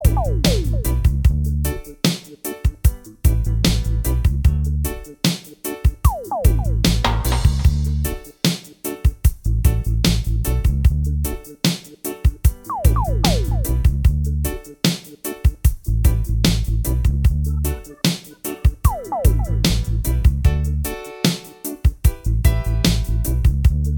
Minus All Guitars Reggae 3:33 Buy £1.50